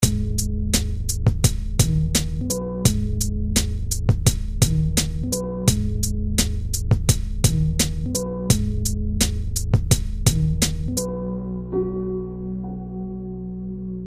mp3,220k][mp3,246k] Рэп